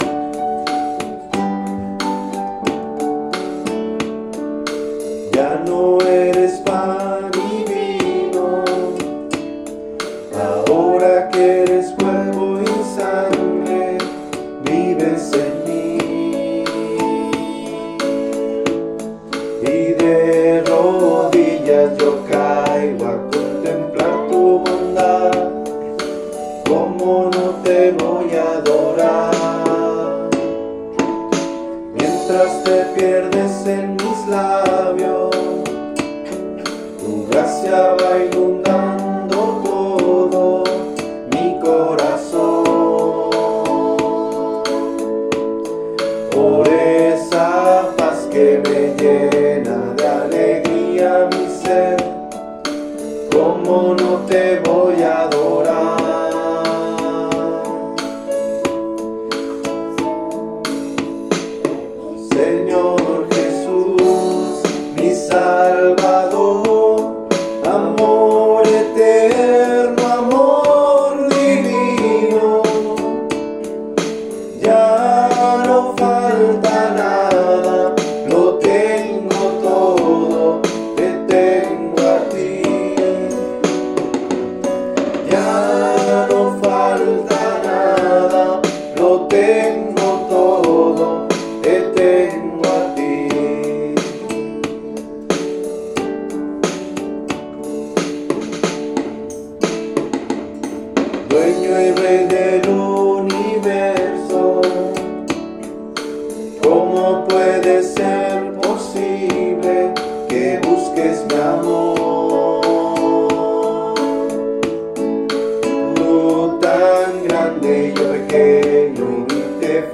Ritmo: 34B
Tempo: 90